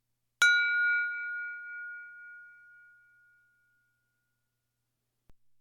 Wine glass 5
bell chime crystal ding wine-glass sound effect free sound royalty free Sound Effects